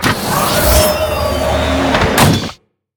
combatdronerecall.ogg